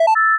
finish-complete.wav